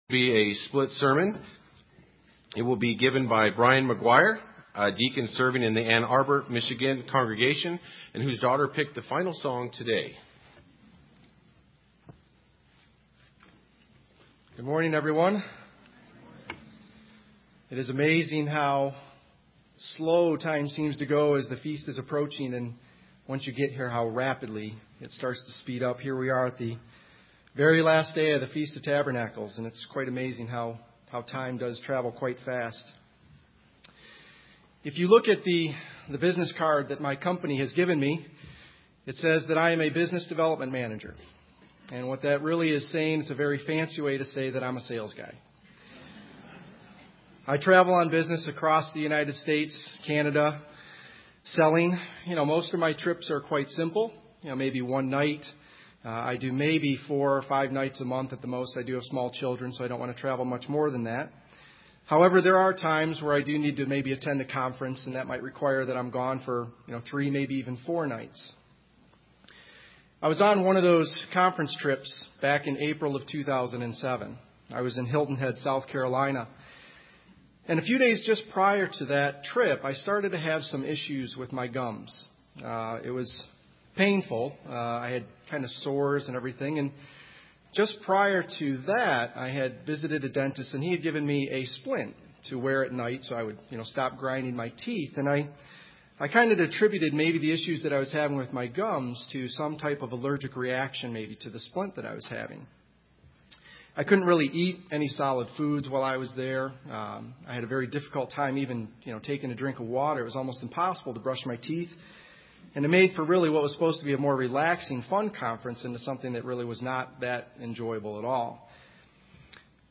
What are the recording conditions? This sermon was given at the Bend, Oregon 2012 Feast site.